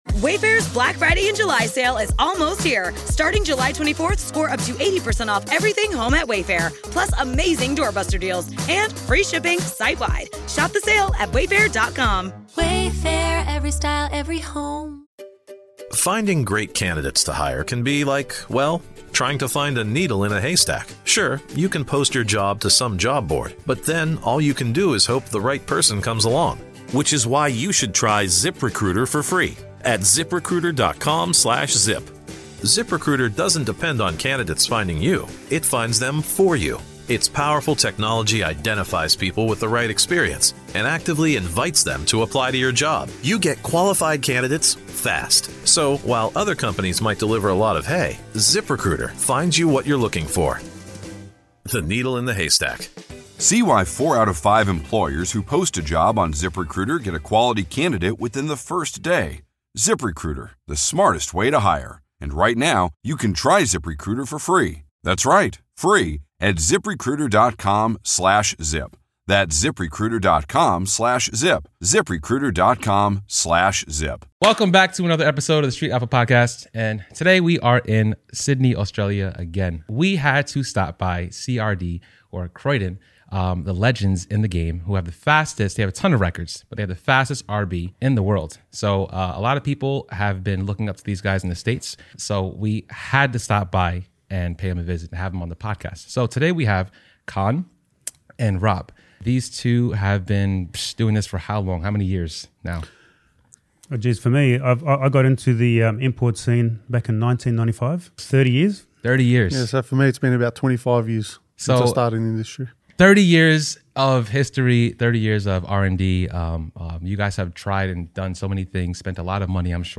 I’m in the studio with my oldest friend, and the singular influence on my passion for classic trucks and anything automotive.